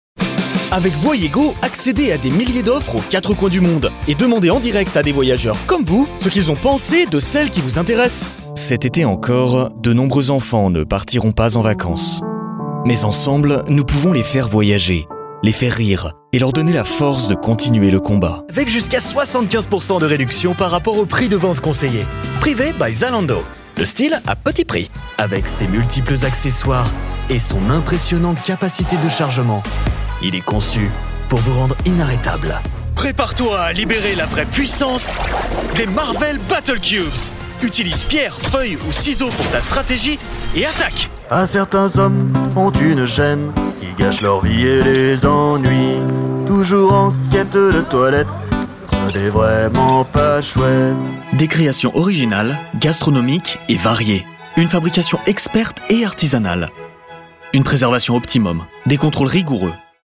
Natural, Versatile, Reliable, Friendly, Corporate
Commercial
His natural mid-low voice sounds professional and trustworthy but also warm and friendly - perfect for corporate and educational content, while his versatility allows him to voice a wide range of characters and commercials.